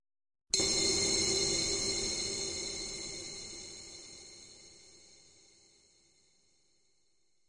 描述：闪闪发光的玻璃
Tag: 微光 混响 玻璃